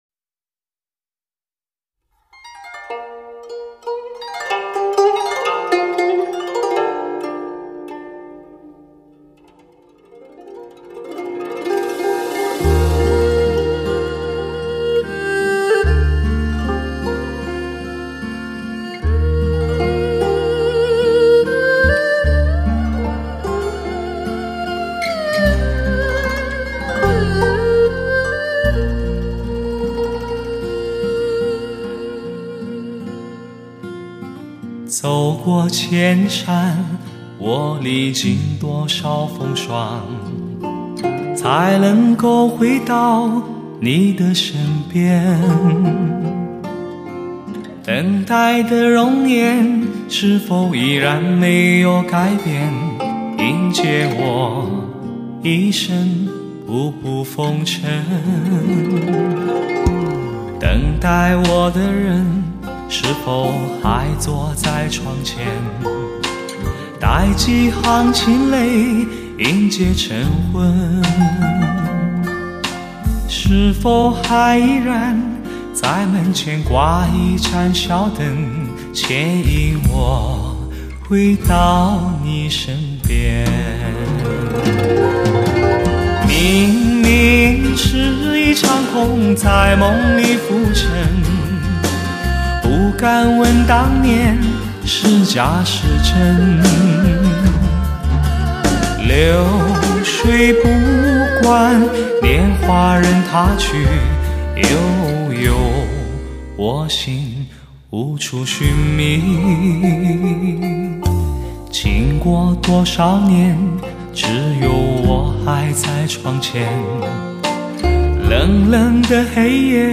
史上最具HI-FI震撼的发烧男声
震撼全场的音效，吸引所有目光的美妙靓声
德国黑胶技术+尖端科技K2HD=显示完美监听效果